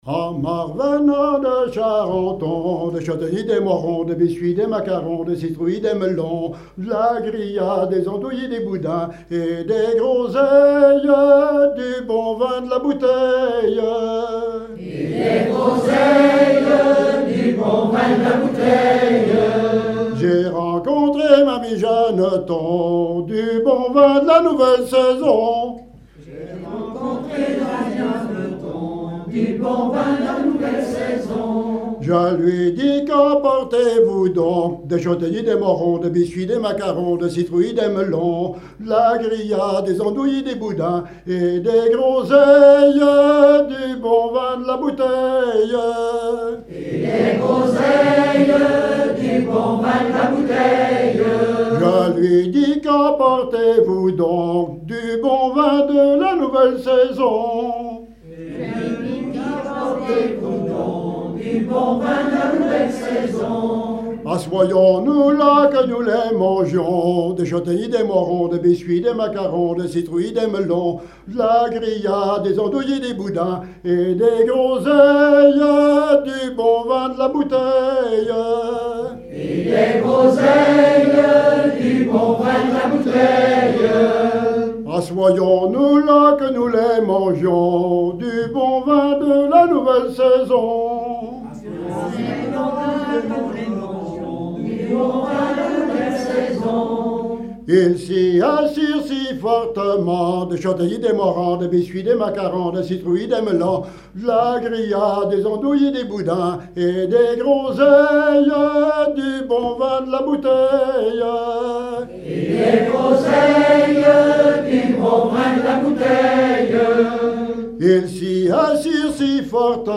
Enfantines - rondes et jeux
Veillée
Pièce musicale inédite